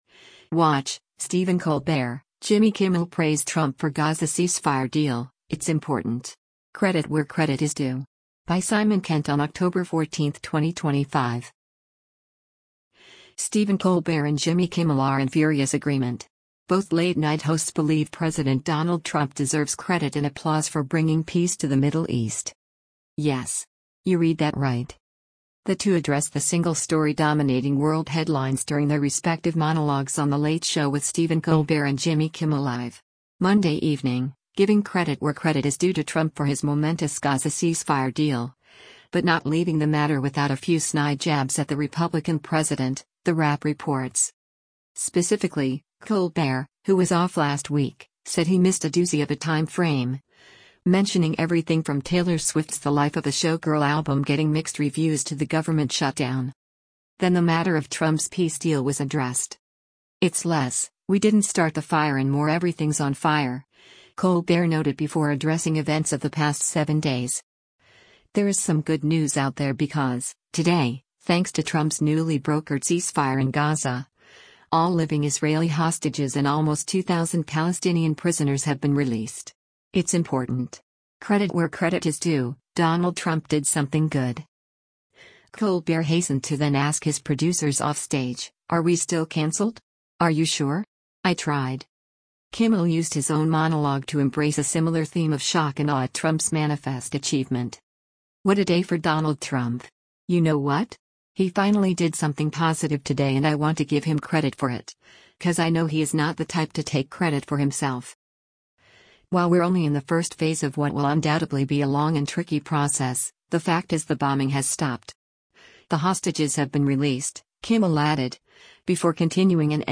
The two addressed the single story dominating world headlines during their respective monologues on The Late Show With Stephen Colbert and Jimmy Kimmel Live! Monday evening, giving “credit where credit is due” to Trump for his momentous Gaza ceasefire deal — but not leaving the matter without a few snide jabs at the Republican president, The Wrap reports.